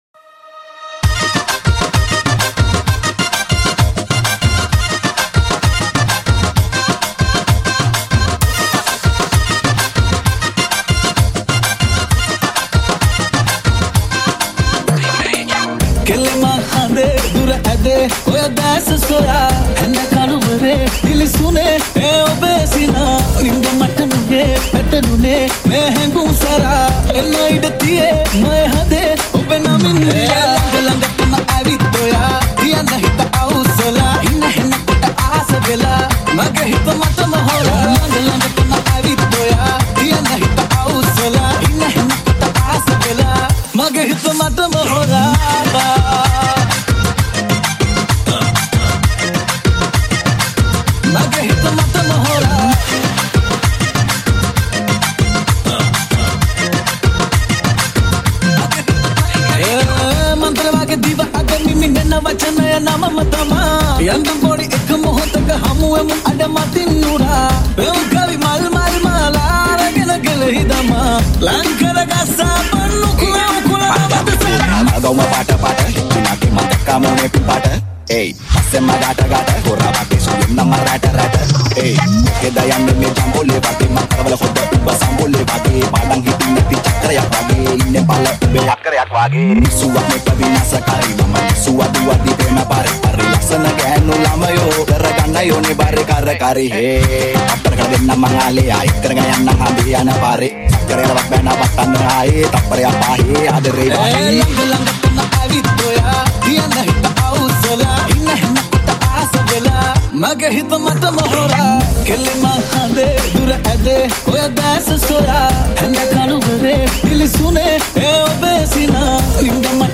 Papare Beat Remix